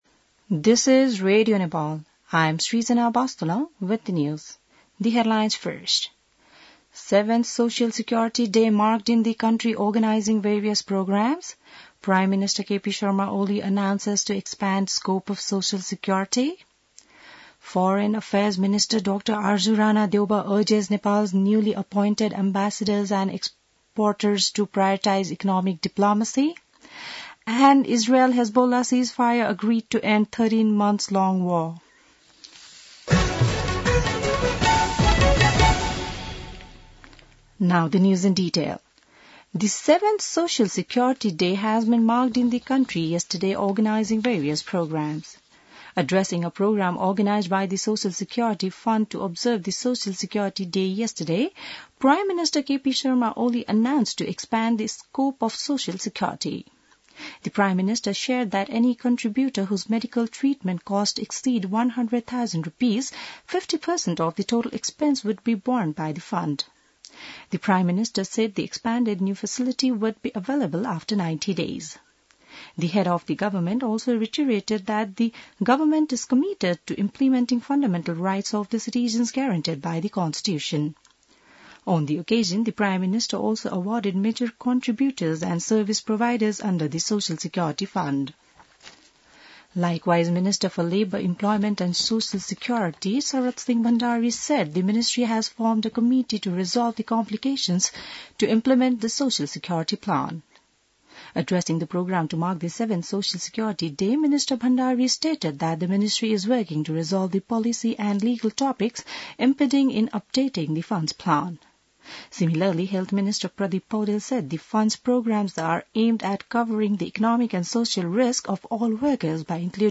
बिहान ८ बजेको अङ्ग्रेजी समाचार : १३ मंसिर , २०८१